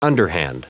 Prononciation du mot underhand en anglais (fichier audio)
Prononciation du mot : underhand